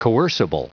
Prononciation du mot coercible en anglais (fichier audio)
Prononciation du mot : coercible